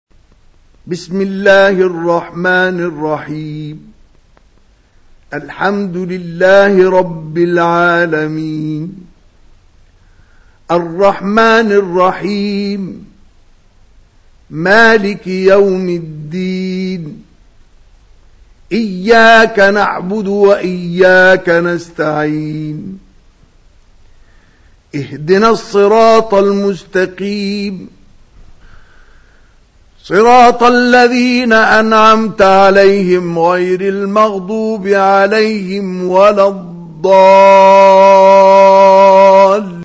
Mushaf recited by Mustafa Ismael
• Rewaya: Hafs from 'Aasem
• Mushaf type: Mourattal